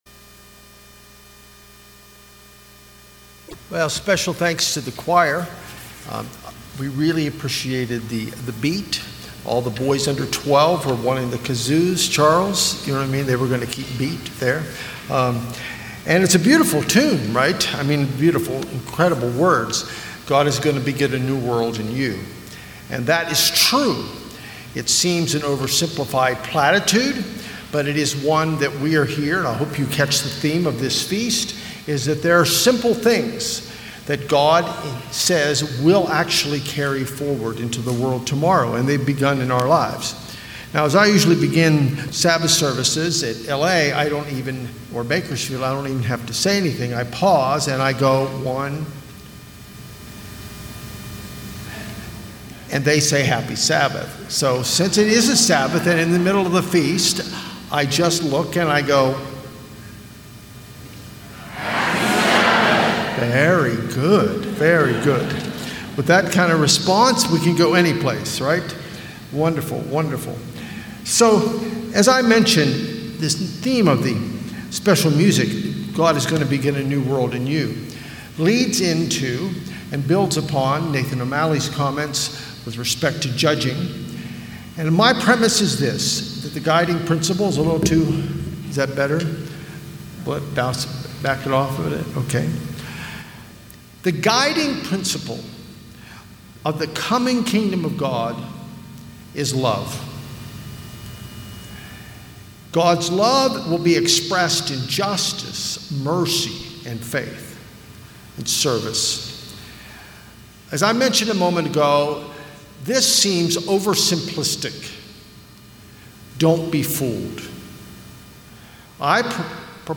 Sermons
Given in Temecula, California